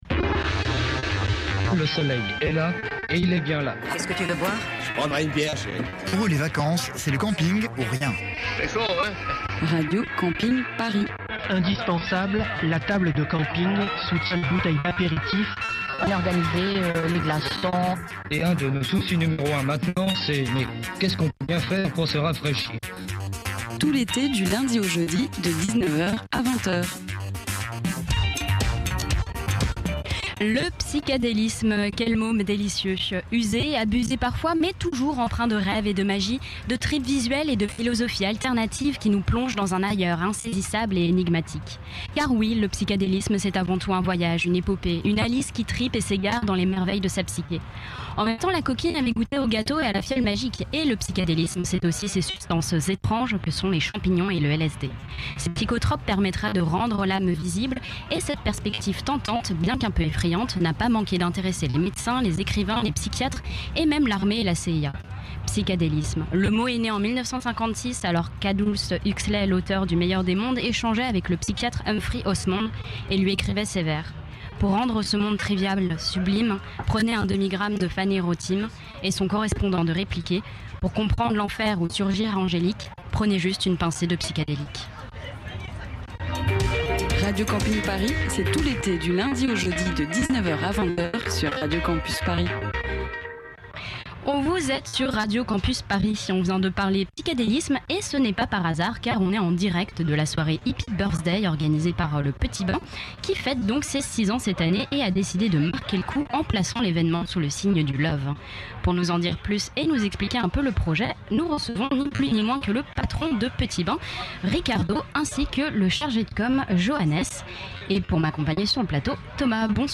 Le 6 juillet Radio Camping était sur la terrasse de Petit Bain pour fêter leur six ans d'existence. L'occasion aussi de célébrer les 50 ans du Summer of Love, un rassemblement de la jeunesse sur la baie de San Francisco autour de la contre-culture.